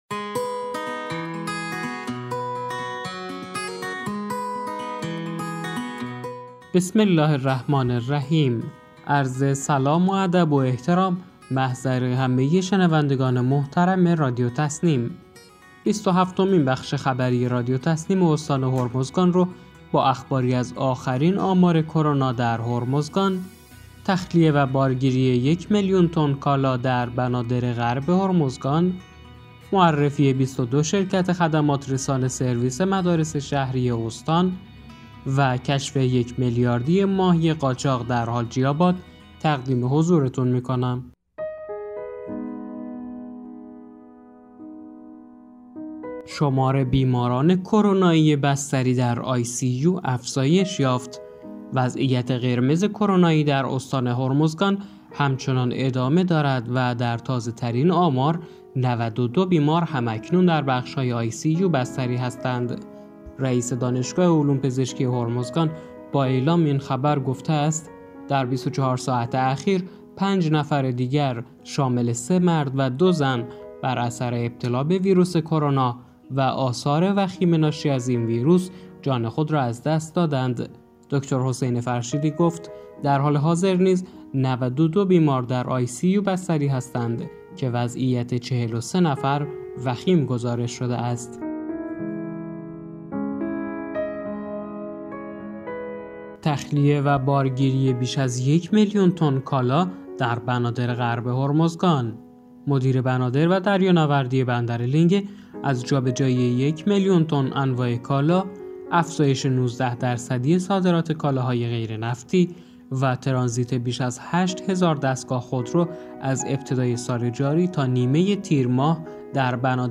به گزارش خبرگزاری تسنیم از بندرعباس، بیست و هفتمین بخش خبری رادیو تسنیم استان هرمزگان با اخباری از آخرین آمار کرونا در هرمزگان، تخلیه و بارگیری یک میلیون تن کالا در بنادر غرب هرمزگان، معرفی 22 شرکت خدمات‌رسان سرویس مدارس شهری استان و کشف یک میلیاردی ماهی قاچاق در حاجی آباد، منتشر شد.